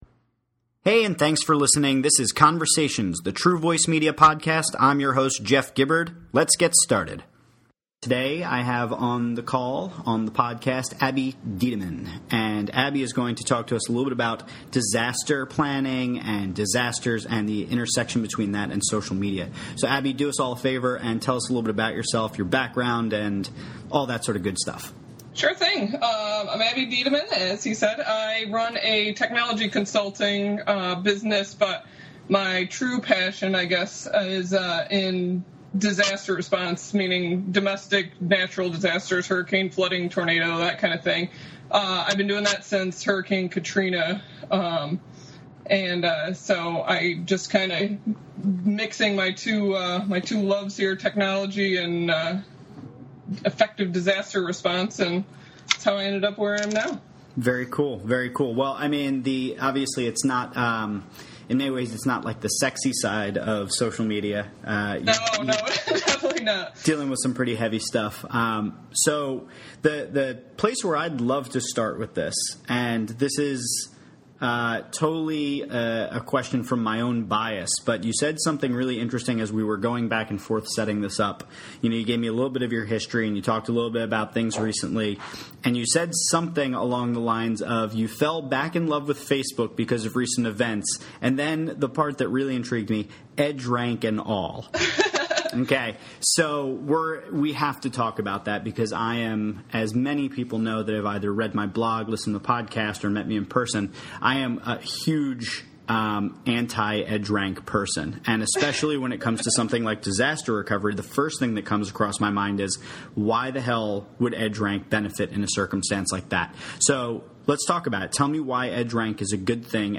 Conversations: A True Voice Media Podcast Crisis Alert!